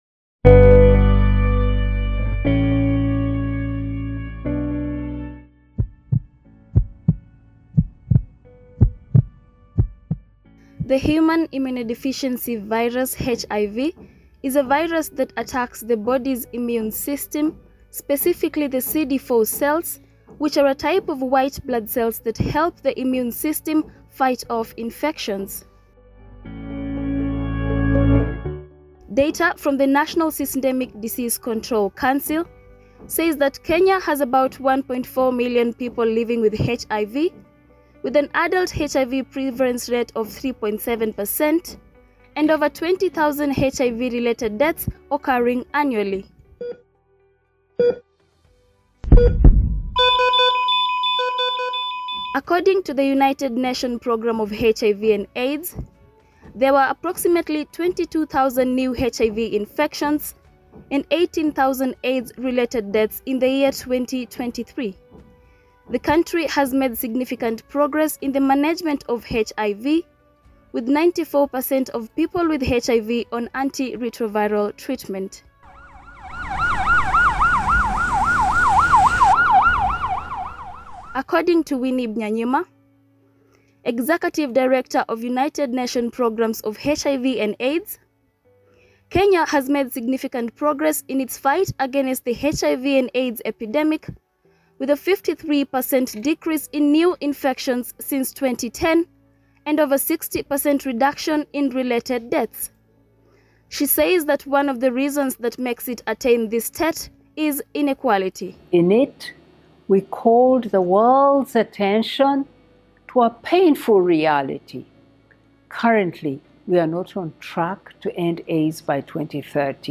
RADIO DOCUMENTARY
RADIO-DOCUMENTARYKENYAS-FIGHT-AGAINST-HIV.mp3